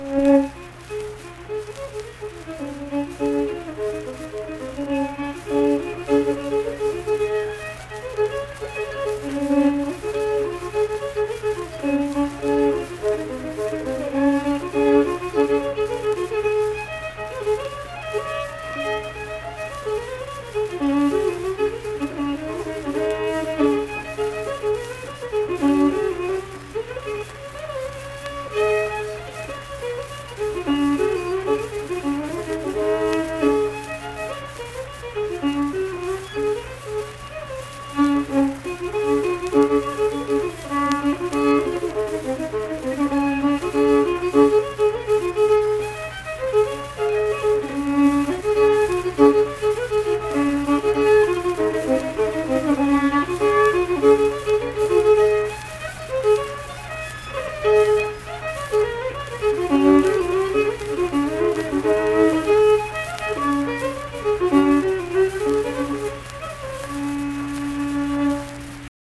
Unaccompanied fiddle music
Performed in Ziesing, Harrison County, WV.
Instrumental Music
Fiddle